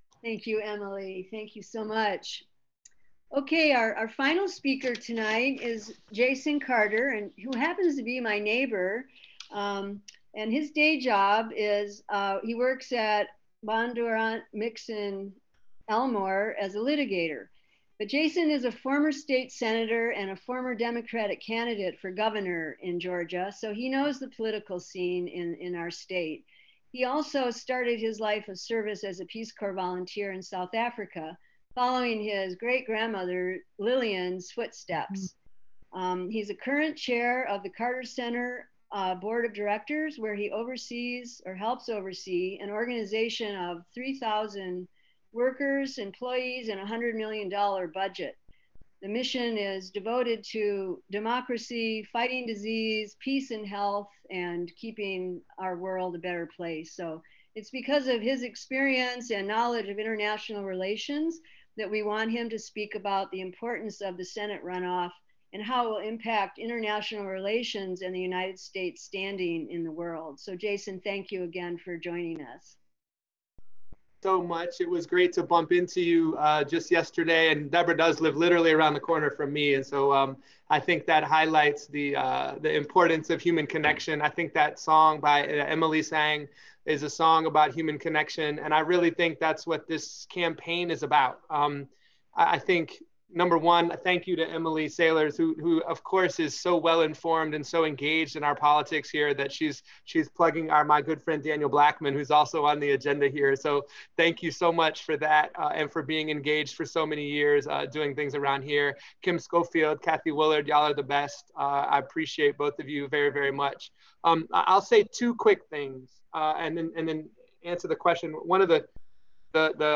(audio captured from zoon meeting)